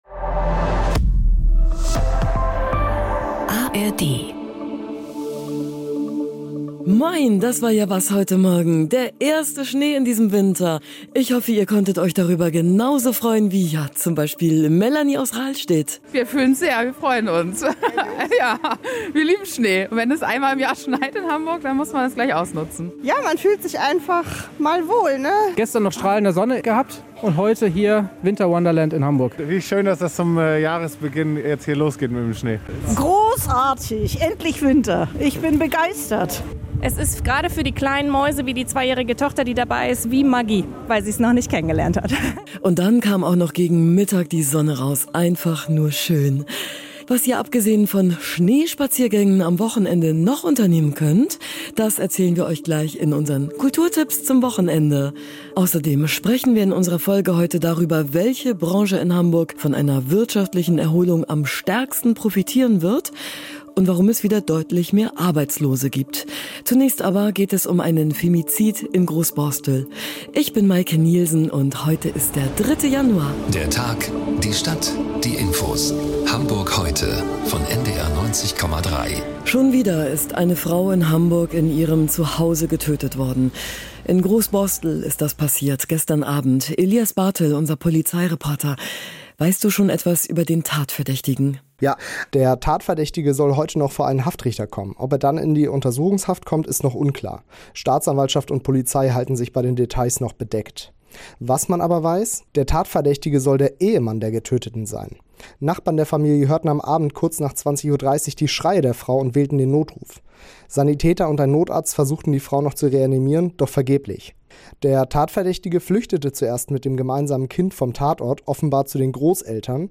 Unsere Reporterinnen und Reporter sind für Sie sowohl nördlich als auch südlich der Elbe unterwegs interviewen Menschen aus Wirtschaft, Gesellschaft, Politik, Sport und Kultur.
… continue reading 498 afleveringen # NDR 90,3 # NDR 90 # Tägliche Nachrichten # Nachrichten # St Pauli